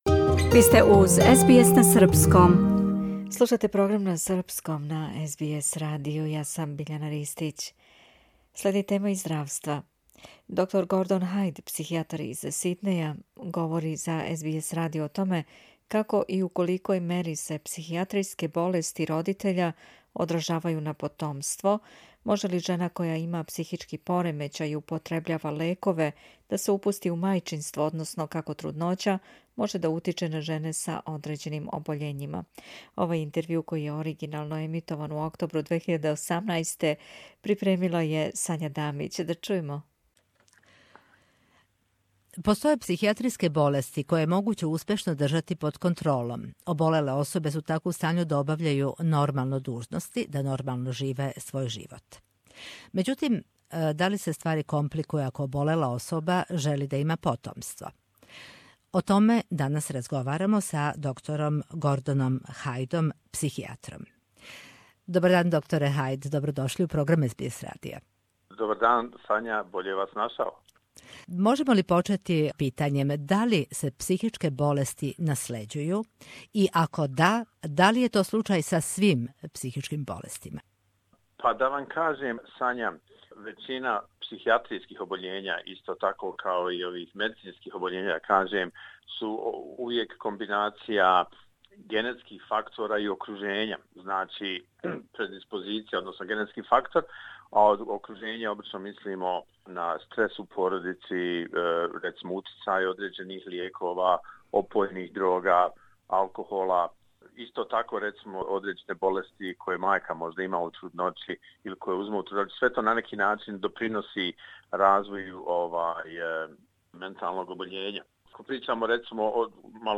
Овај интервју који је оригинално емитован у октобру 2018.